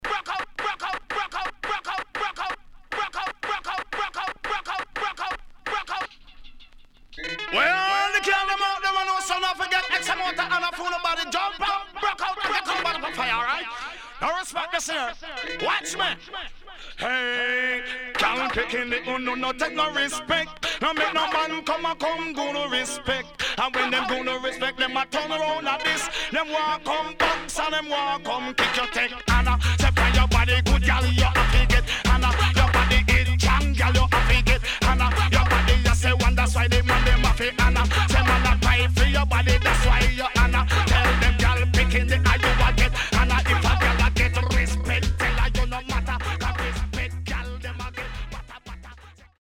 Nice Deejay